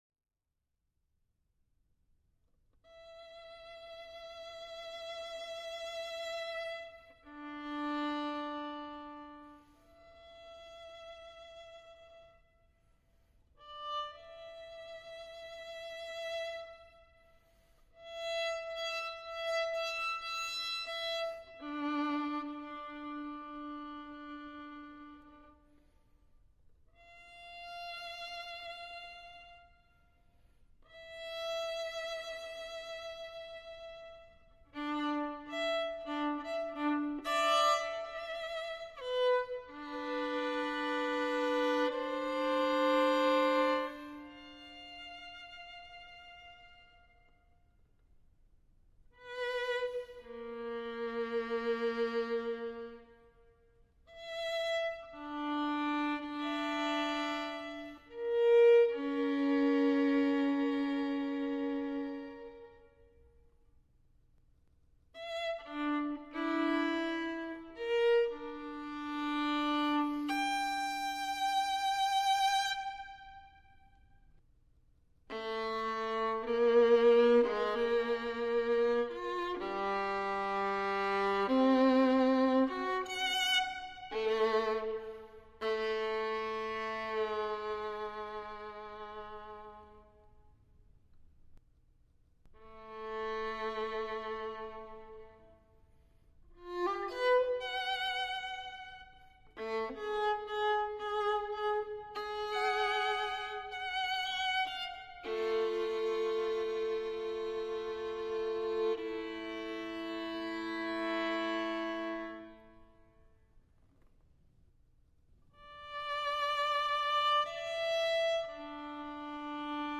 ‘any mournful sound’ Outtake/St Michael’s Highgate London Summer 2017